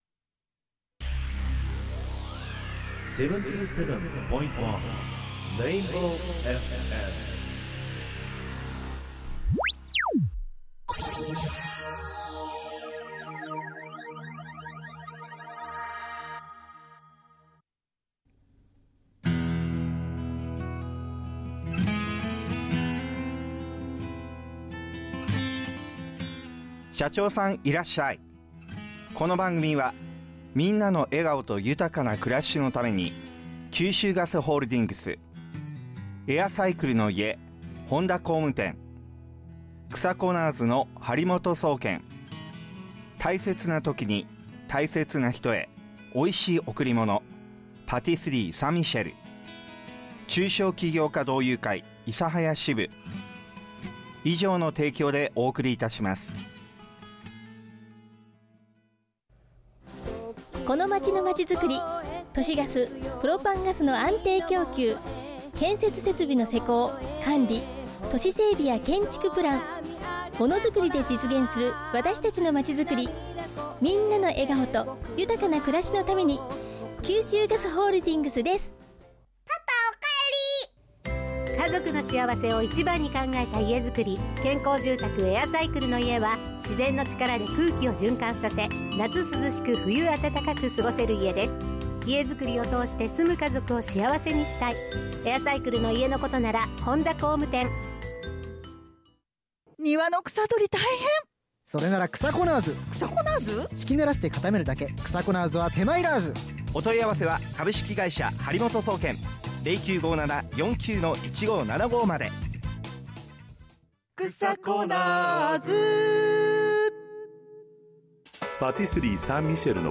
今回の社長さんいらっしゃ～い！は事前収録スペシャルをお送りいたします。